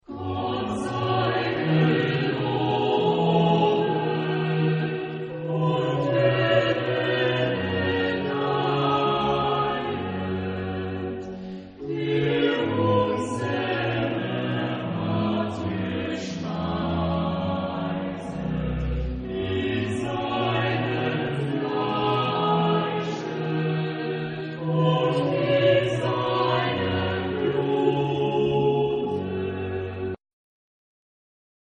Género/Estilo/Forma: Sagrado
Tipo de formación coral: SATB  (4 voces Coro mixto )